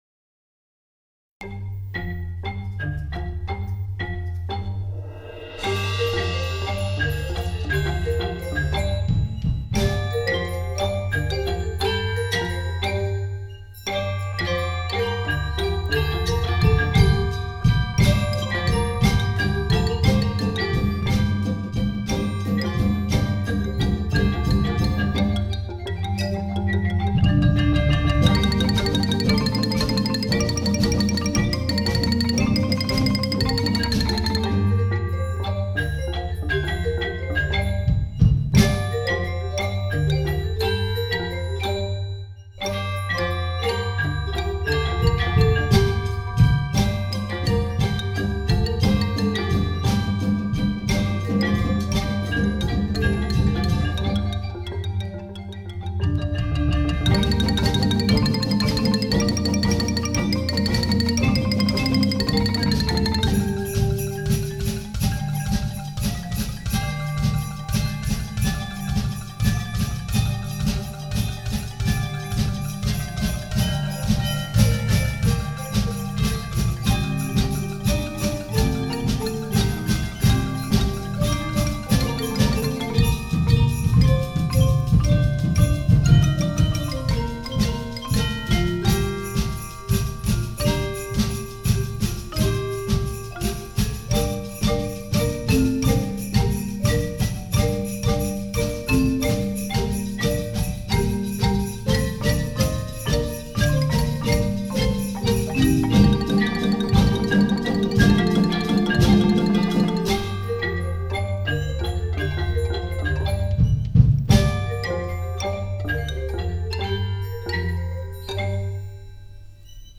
Voicing: 9 Players